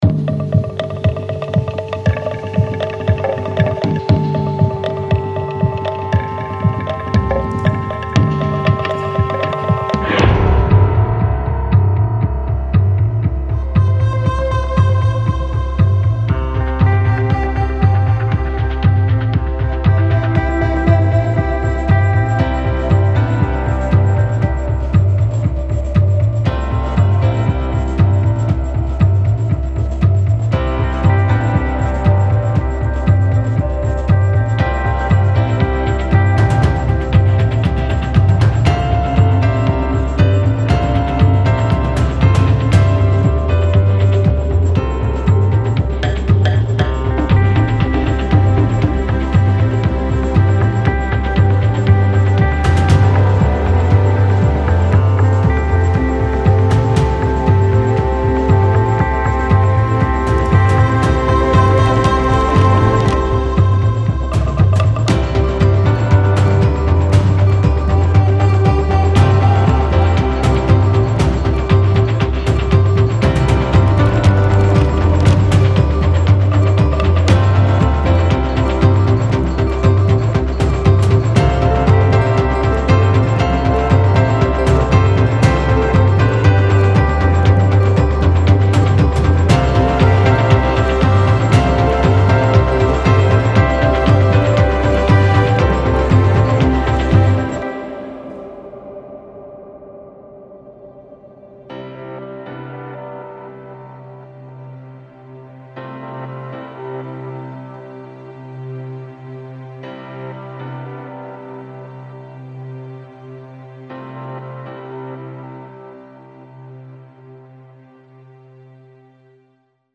Largo [40-50] passion - ensemble instruments - - -